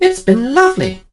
barley_die_02.ogg